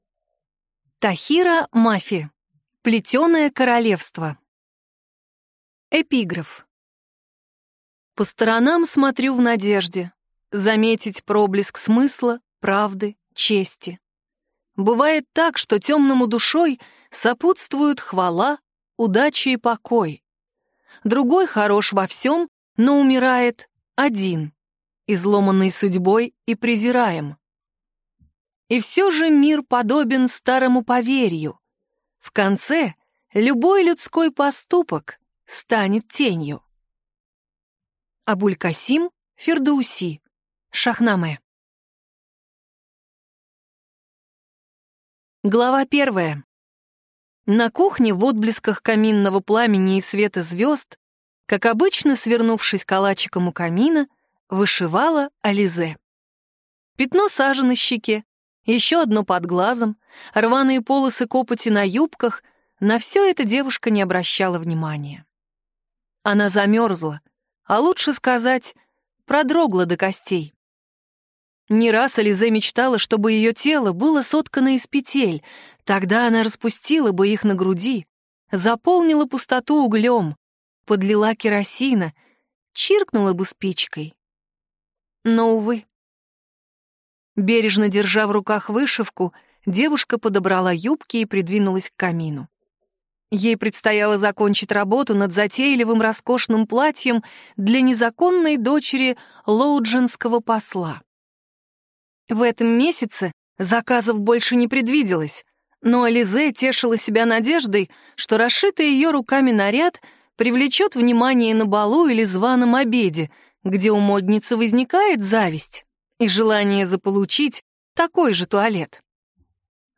Аудиокнига Плетеное королевство | Библиотека аудиокниг